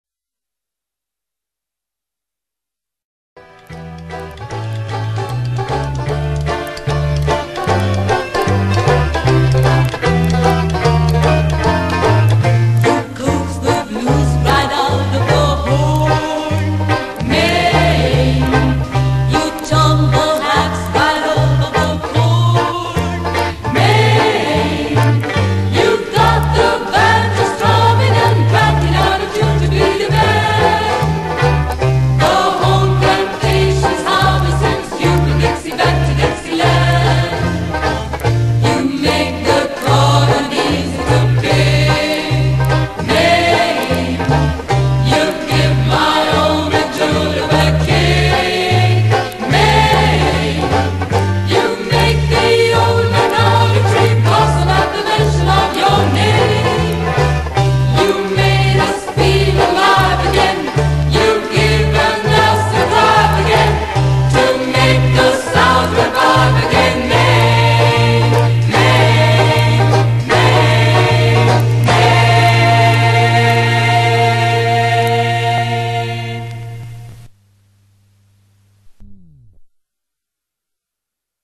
one of the warmest dixies